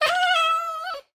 Minecraft Version Minecraft Version 1.21.5 Latest Release | Latest Snapshot 1.21.5 / assets / minecraft / sounds / mob / cat / ocelot / death3.ogg Compare With Compare With Latest Release | Latest Snapshot
death3.ogg